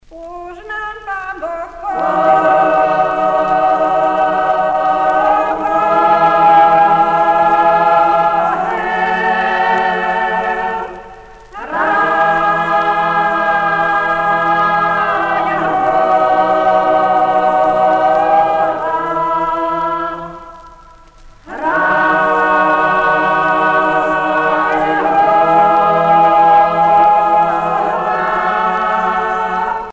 Hudci a spevaci z trencianského povazia
Pièce musicale éditée